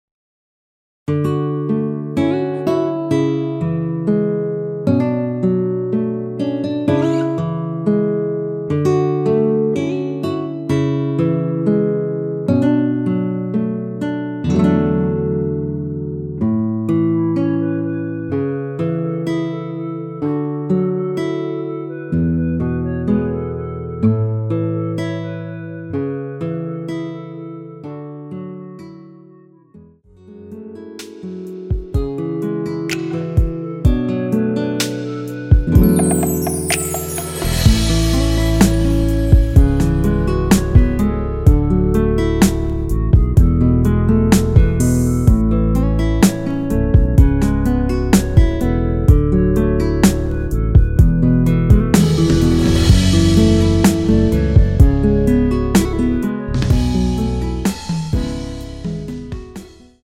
원키에서(-3)내린 멜로디 포함된 MR입니다.
노래방에서 노래를 부르실때 노래 부분에 가이드 멜로디가 따라 나와서
앞부분30초, 뒷부분30초씩 편집해서 올려 드리고 있습니다.